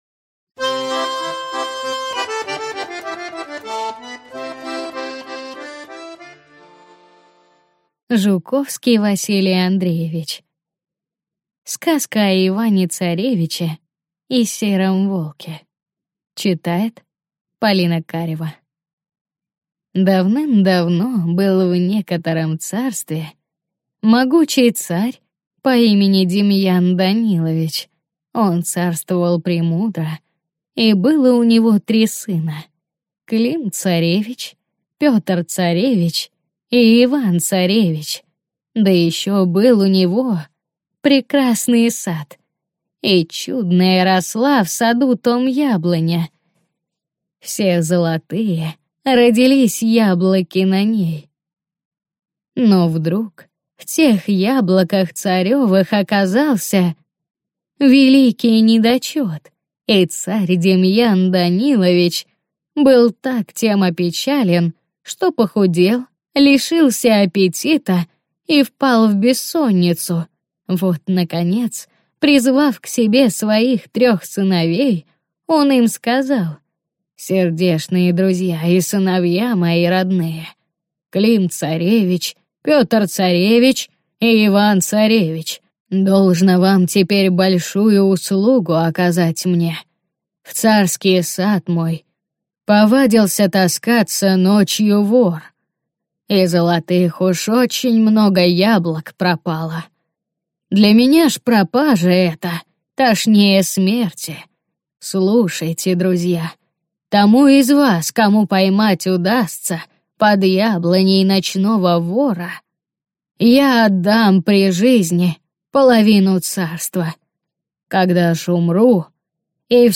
Аудиокнига Сказка о Иване-царевиче и Сером Волке | Библиотека аудиокниг